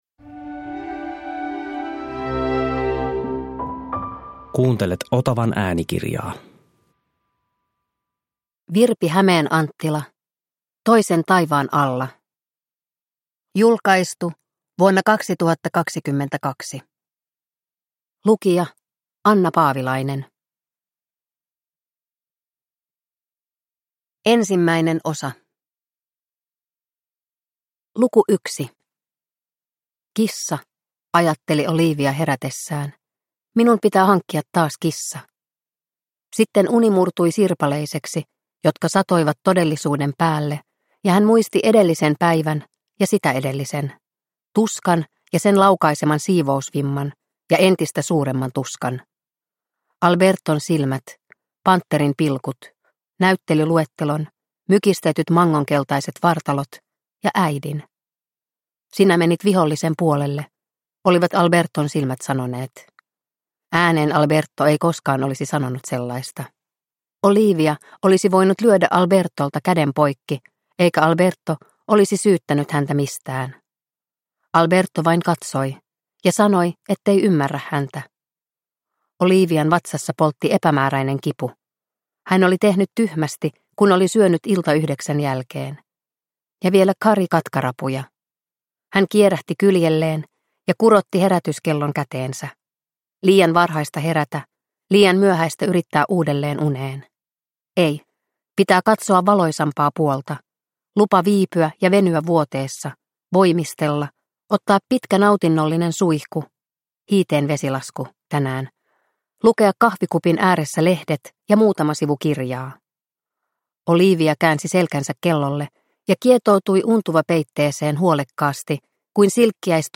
Toisen taivaan alla – Ljudbok – Laddas ner